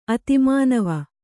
♪ ati mānava